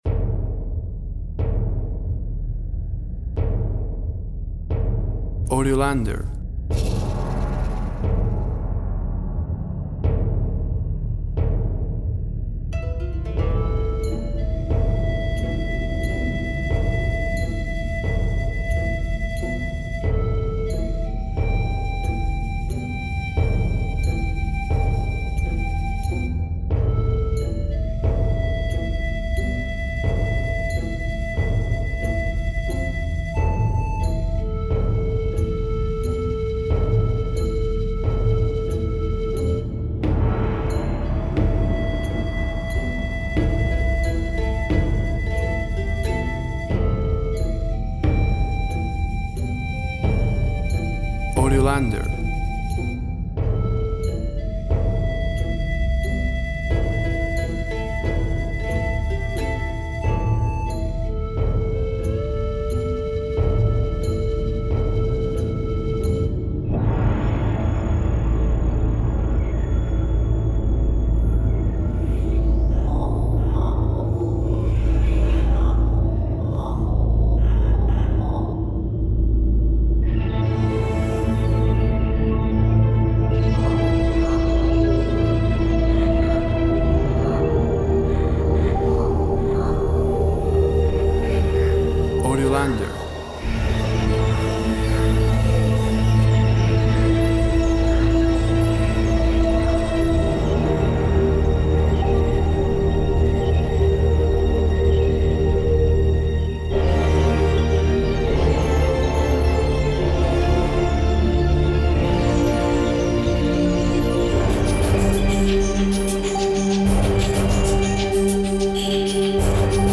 Ethnic and fantasy sci-fi type track.
Tempo (BPM) 90